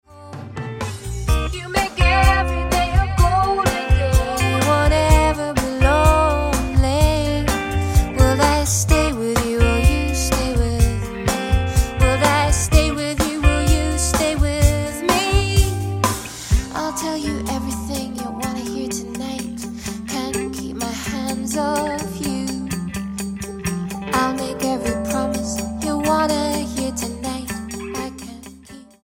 STYLE: Roots/Acoustic
spine-tingling, sultry voice